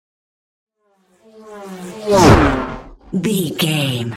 Pass by fast speed engine
Sound Effects
pass by
car
vehicle